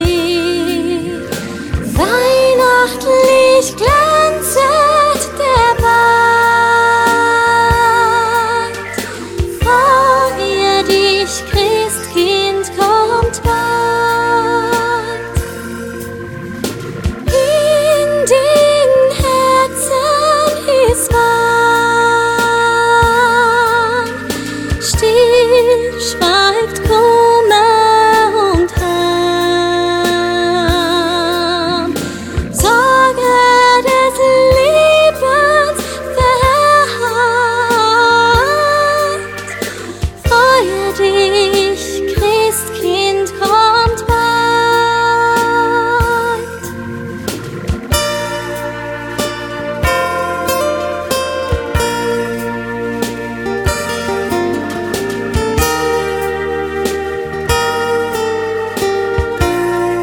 Diesmal auf Deutsch und ganz akkustisch
Kinderlieder